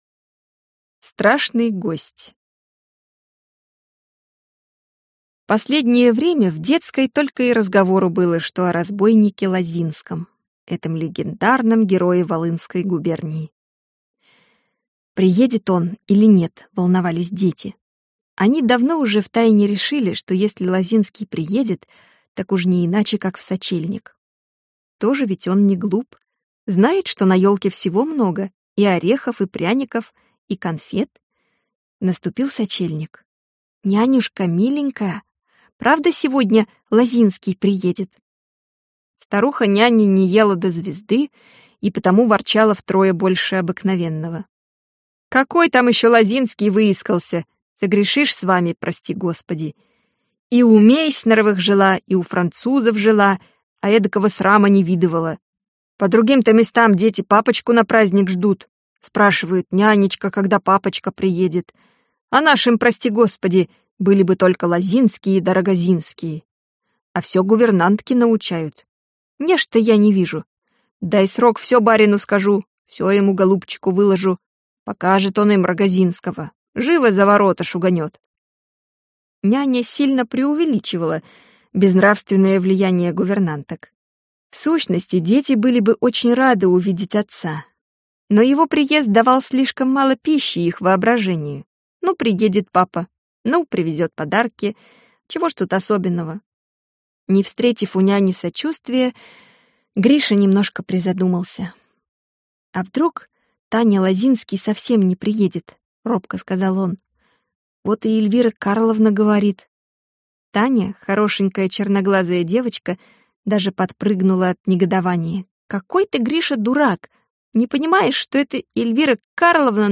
Аудиокнига Тэффи и её сёстры: Мирра, Мюргит и Элио | Библиотека аудиокниг